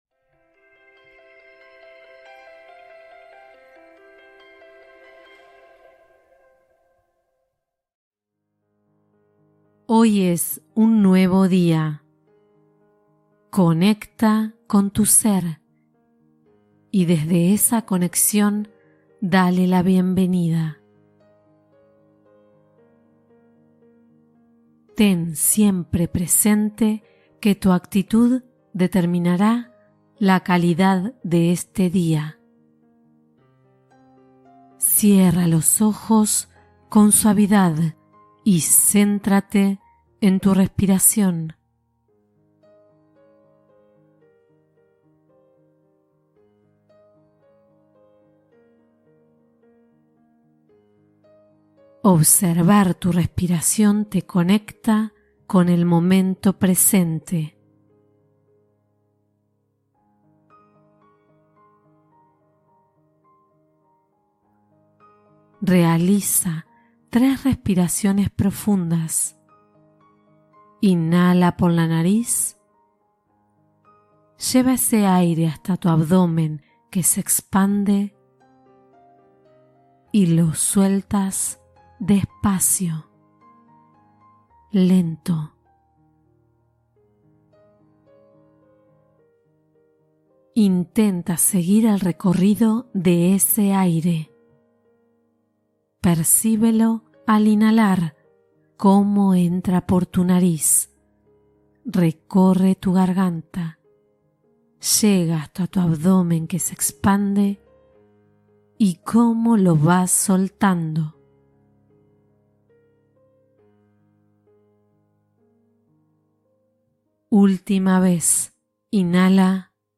Comienza el día con alegría y felicidad con esta meditación guiada